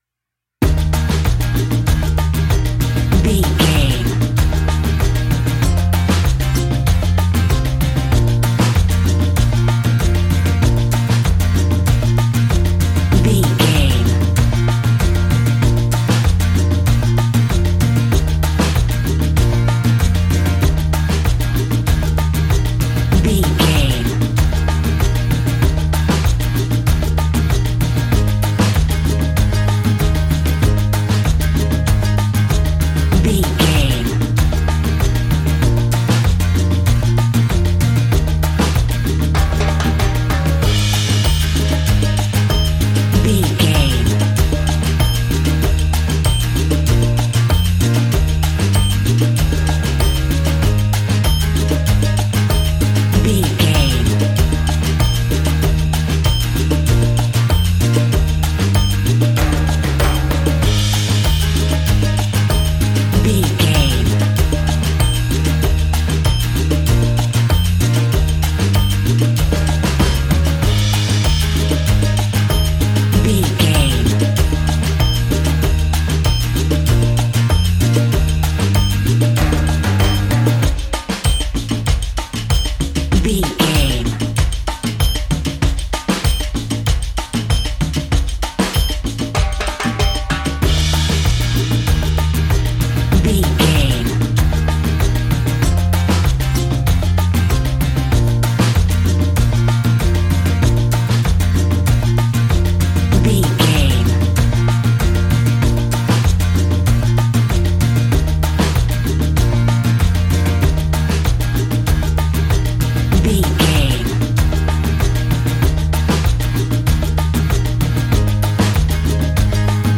Classic reggae music with that skank bounce reggae feeling.
Ionian/Major
E♭
cheerful/happy
mellow
fun
drums
electric guitar
percussion
horns
electric organ